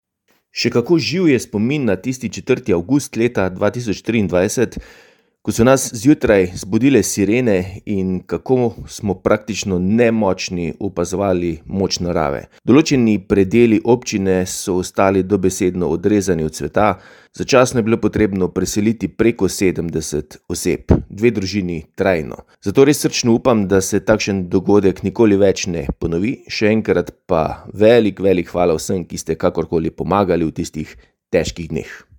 Mestna občina Slovenj Gradec je prav tako utrpela veliko škodo v poplavah. Takole tistega jutra spominja župan Tilen Klugler:
izjava Klugler 1 za splet.mp3